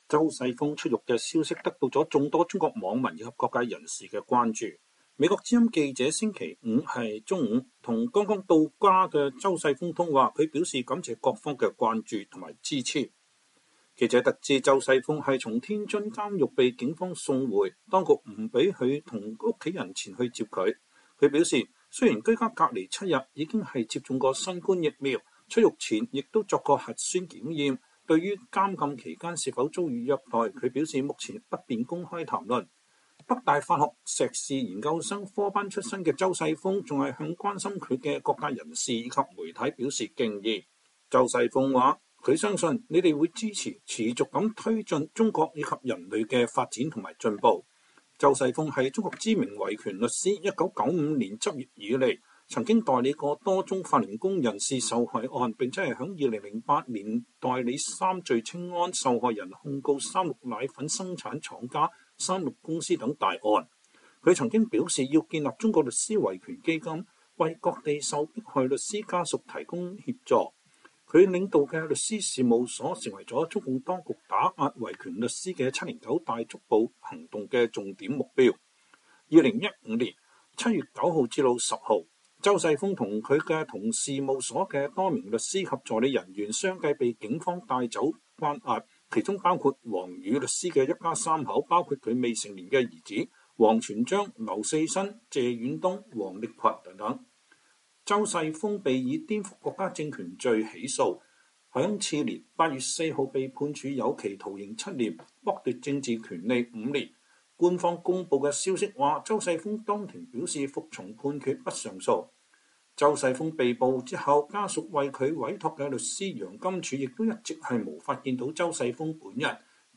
美國之音記者周五中午與剛到家的周世鋒通話，他表示感謝各方關注支持。